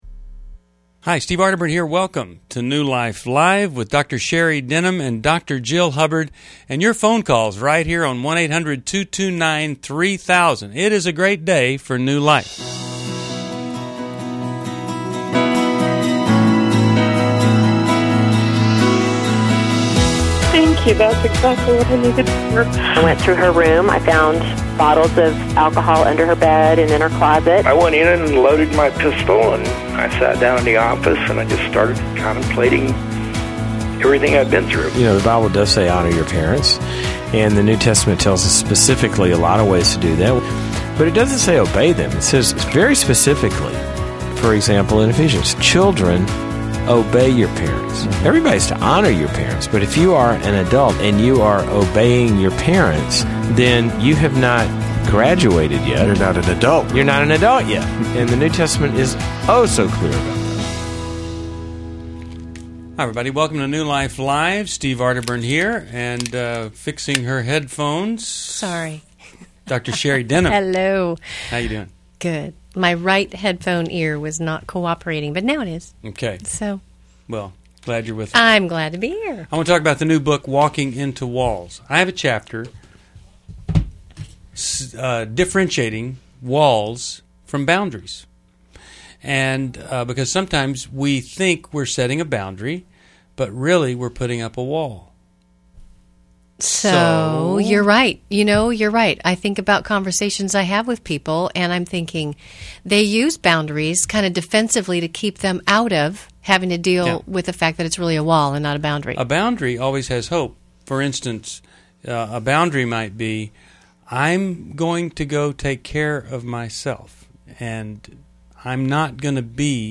Explore relationship challenges on New Life Live: August 11, 2011, as hosts tackle dating dilemmas, boundary setting, and marriage struggles.
Caller Questions: 1.